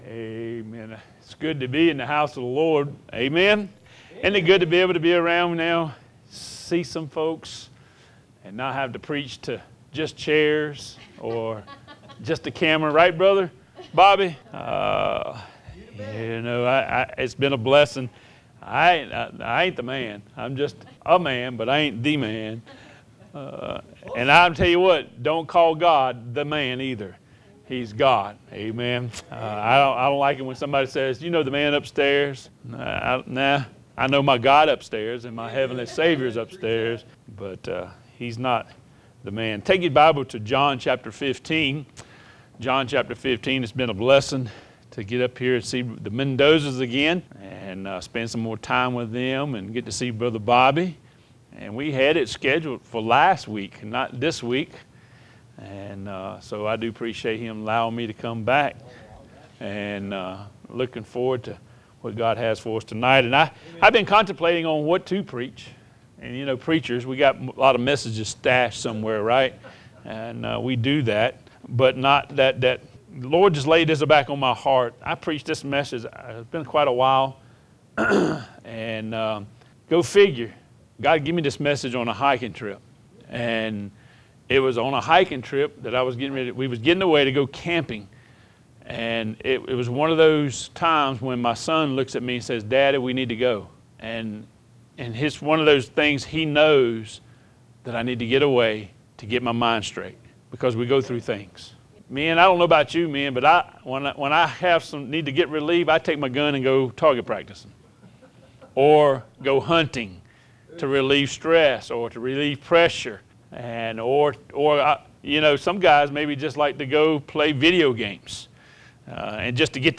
A message from the series "Out of Series." Wednesday night.